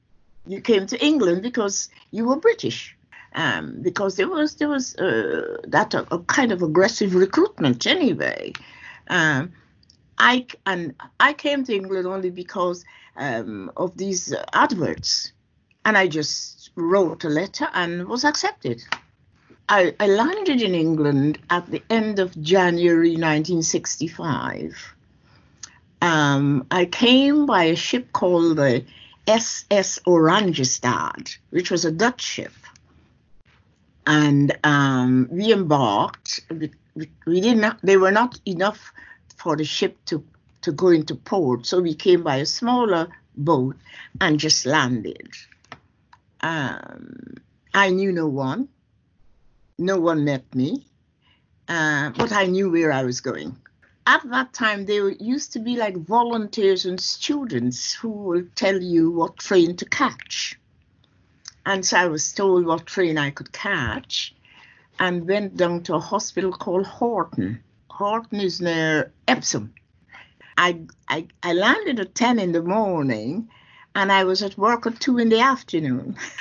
interviewee
This oral history excerpt has been drawn from the scoping project ‘Nationality, Identity and Belonging: An Oral History of the ‘Windrush Generation’ and their Relationship to the British State, 1948-2018'.